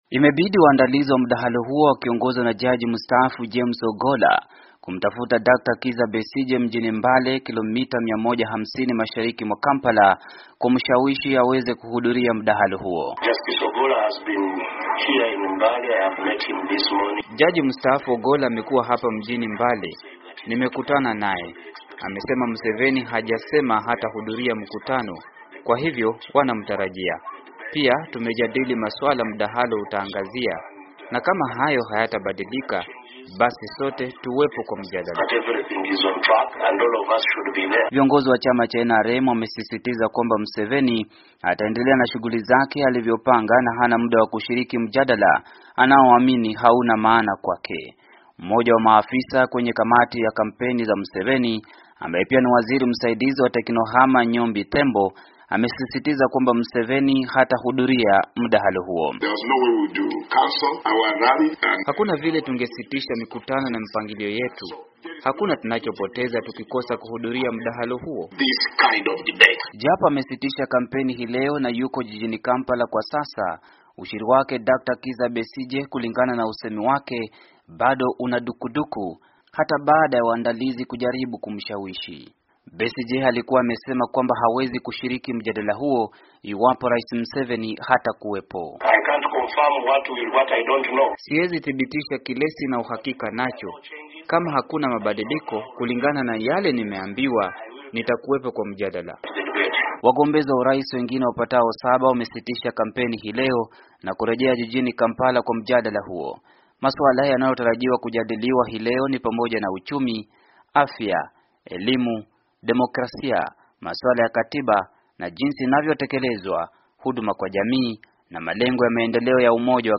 Mwandishi wetu wa Kampala